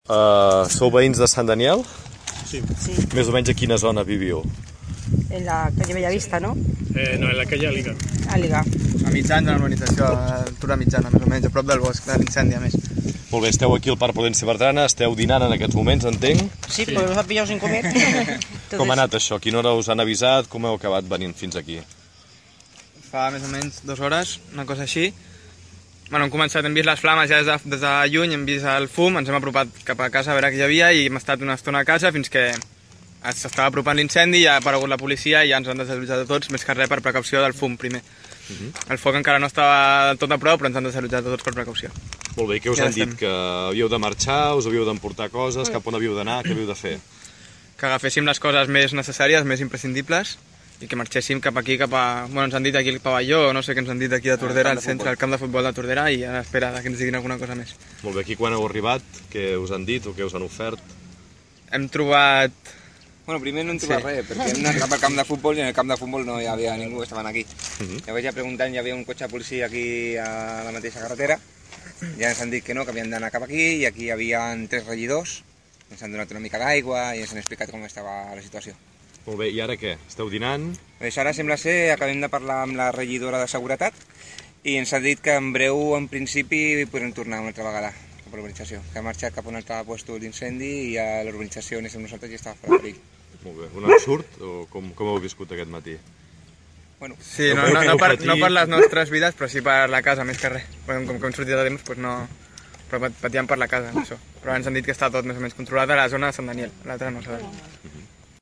Això és el que comentaven, en declaracions a Ràdio Tordera, uns veïns desallotjats que s’han acostat fins el parc.
2Familia_desallotjada.mp3